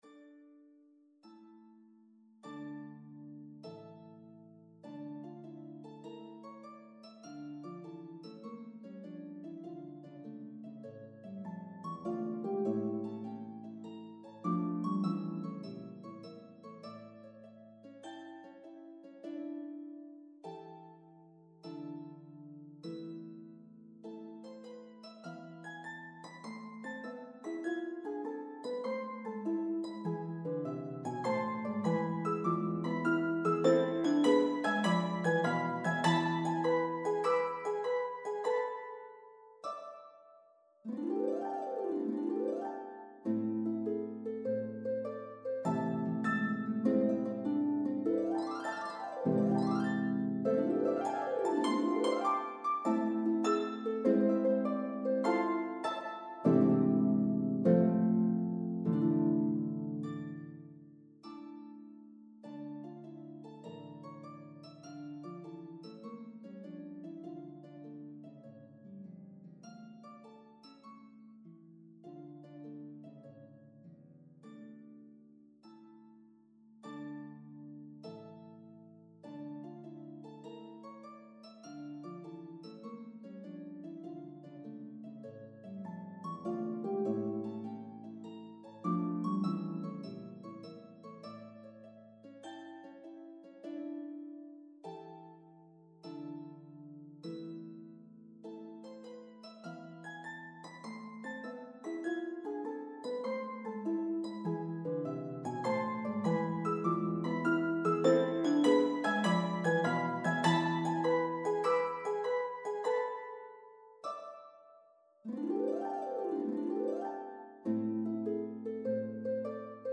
is for two pedal harps. Composed as a canon at the octave
The middle section is slightly jazzy.